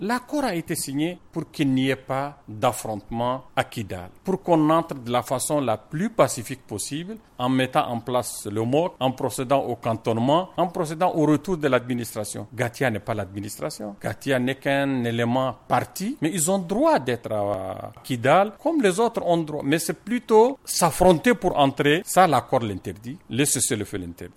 C’est le Représentant spécial du Secrétaire général de l’ONU au Mali qui le dit dans une interview à Studio Tamani.